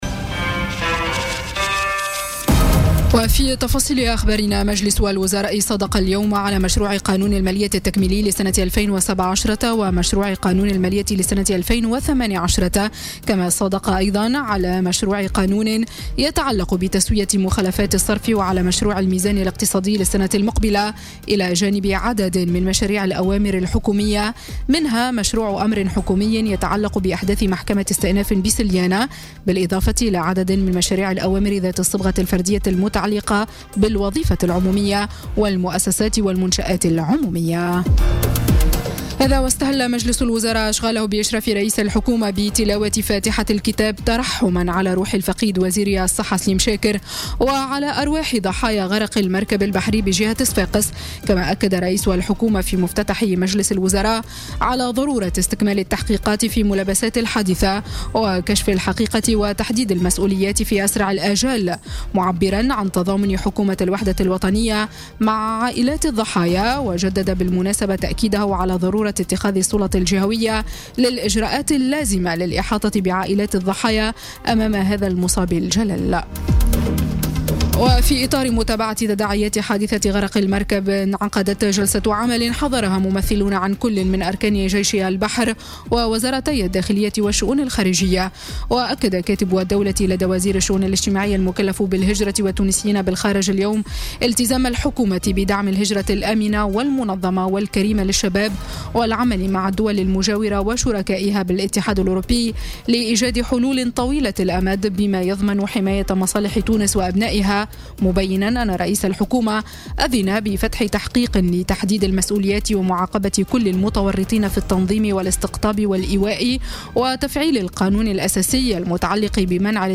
نشرة أخبار السابعة مساء ليوم الخميس 12 أكتوبر 2017